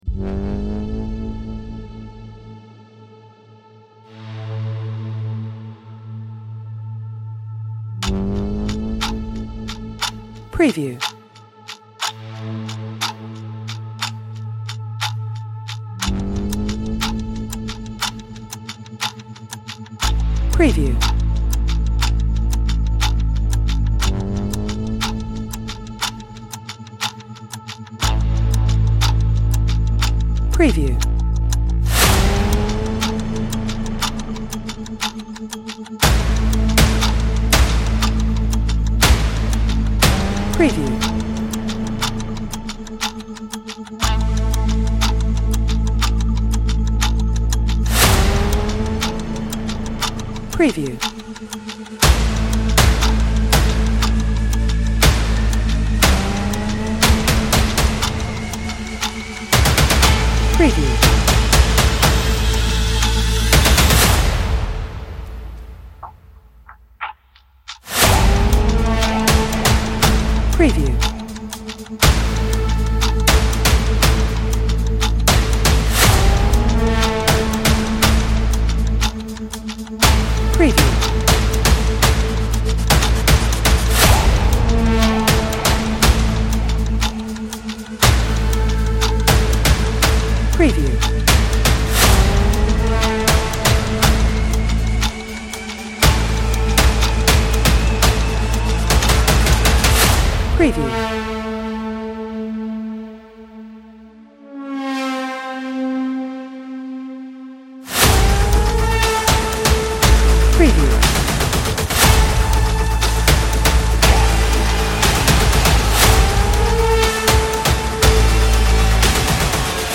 Ticking-Clock Music
Suspenseful Ticking Clock Music for Maximum Impact